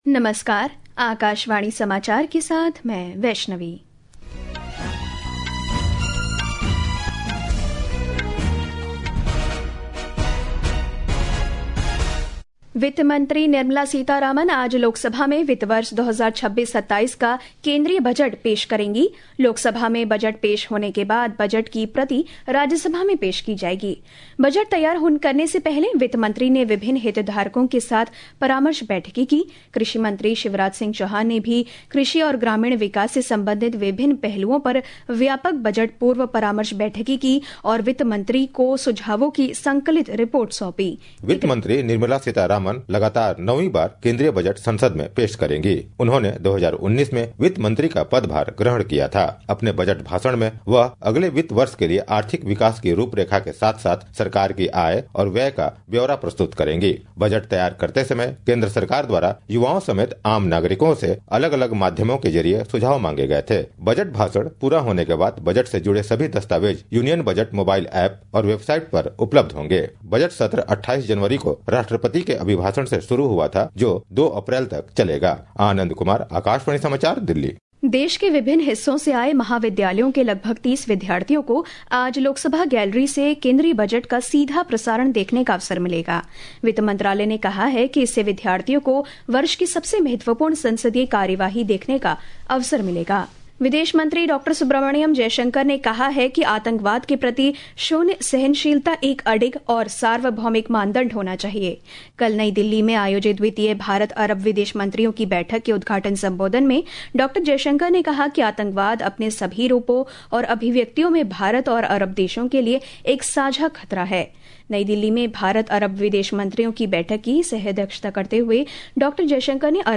National Bulletins
प्रति घंटा समाचार